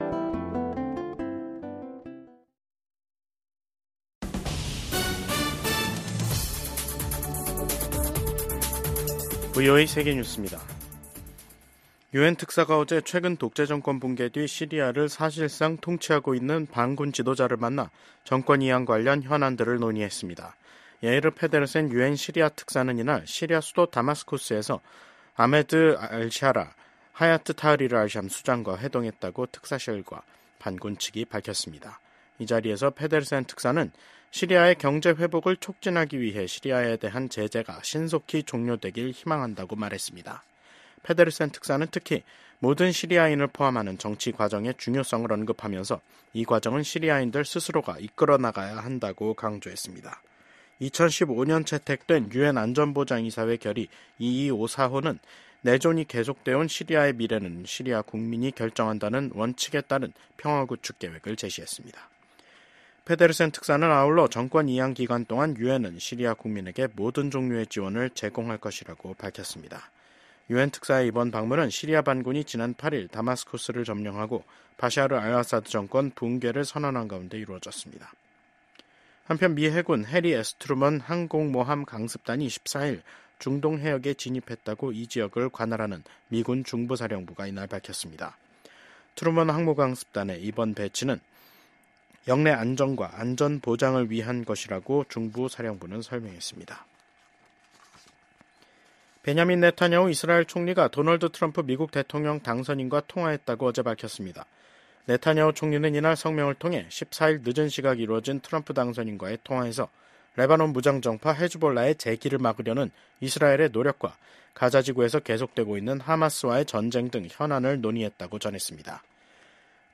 VOA 한국어 간판 뉴스 프로그램 '뉴스 투데이', 2024년 12월 16일 2부 방송입니다. 윤석열 한국 대통령이 탄핵소추안 가결로 직무가 정지되면서 윤석열 정부가 추진해 온 강경기조의 대북정책 동력도 약화될 것이라는 전망이 나옵니다. 미국 정부는 윤석열 대통령 탄핵소추안이 한국 국회에서 가결된 것과 관련해 한국의 민주주의와 법치주의에 대한 지지 입장을 재확인했습니다.